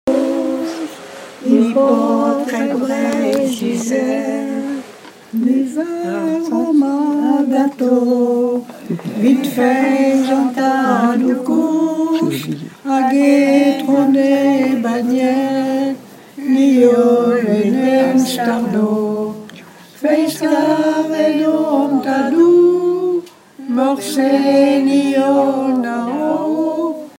Genre strophique
Cantiques et témoignages en breton
Pièce musicale inédite